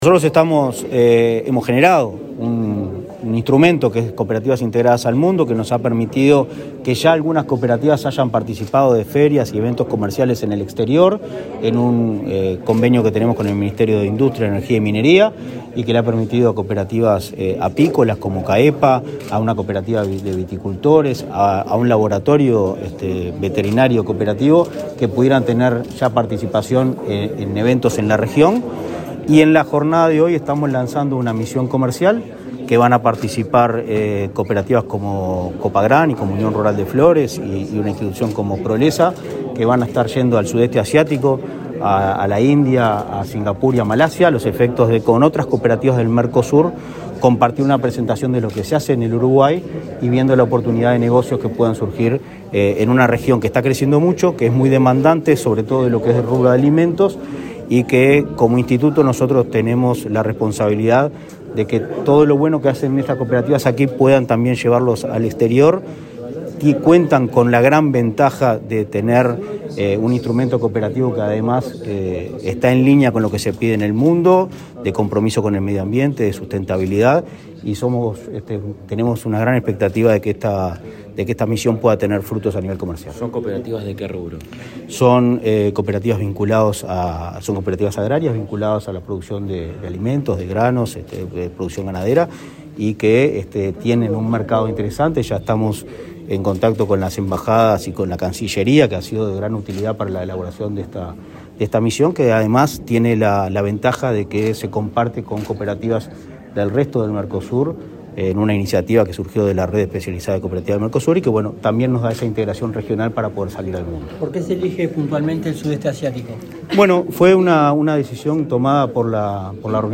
Declaraciones del presidente de Inacoop, Martín Fernández 19/10/2023 Compartir Facebook X Copiar enlace WhatsApp LinkedIn El Instituto Nacional del Cooperativismo (Inacoop) presentó a una misión comercial que viajará al sudeste asiático, en el marco de la política implementada para abrir las cooperativas al mundo. El presidente del organismo, Martín Fernández, dialogó con la prensa luego del acto, para explicar el alcance del apoyo que se brinda.